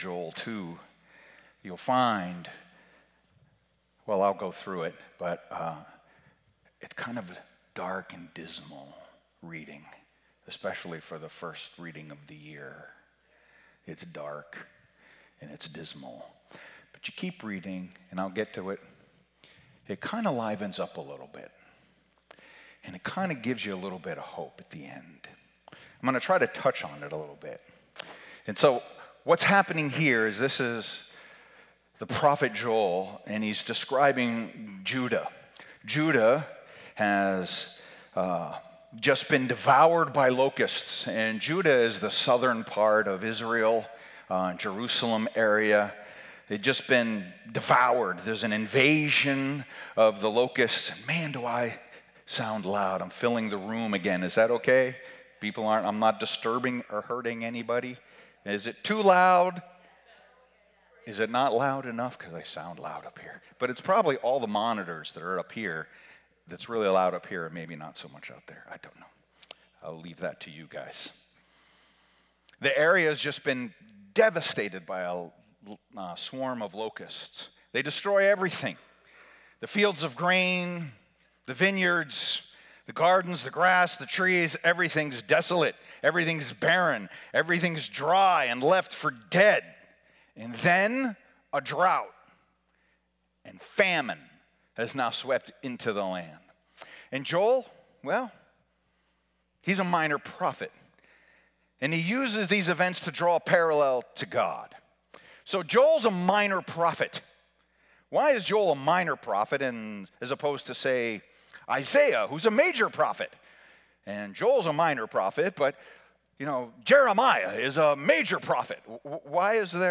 Sermons | New Life Alliance Church